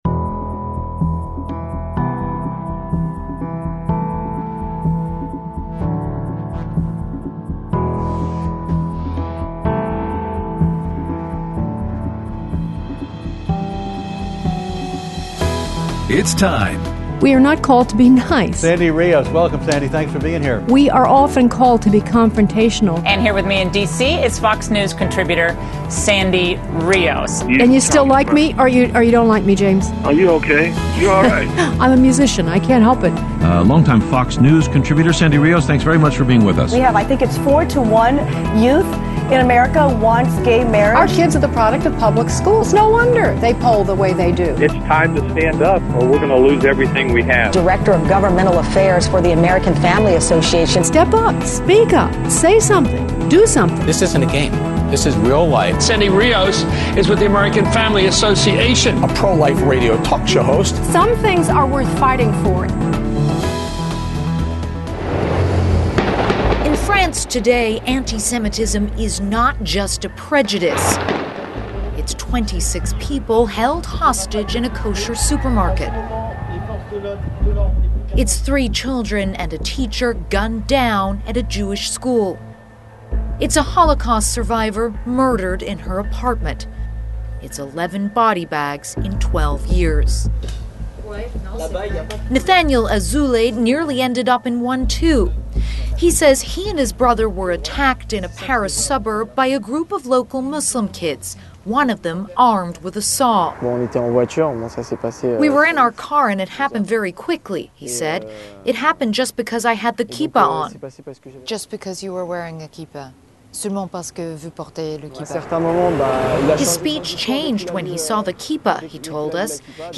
Aired Friday 1/25/19 on AFR 7:05AM - 8:00AM CST